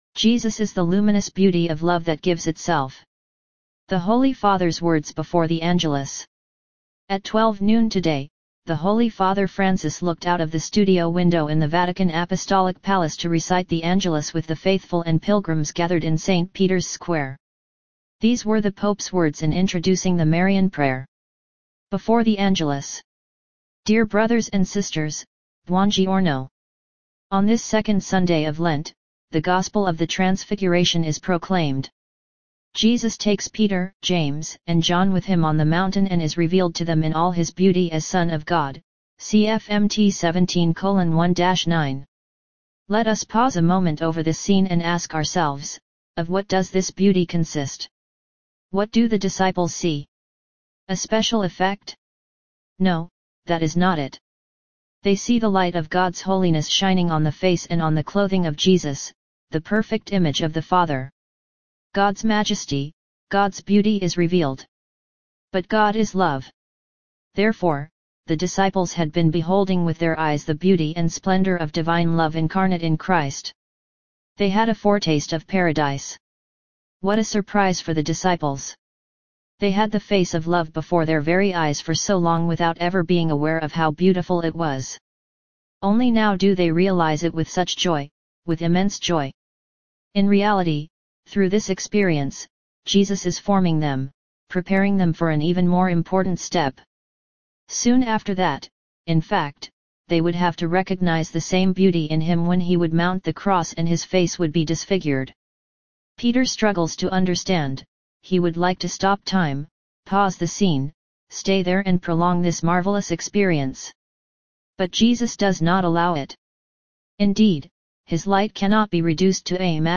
The Holy Father’s words before the Angelus
At 12 noon today, the Holy Father Francis looked out of the studio window in the Vatican Apostolic Palace to recite the Angelus with the faithful and pilgrims gathered in St Peter’s Square.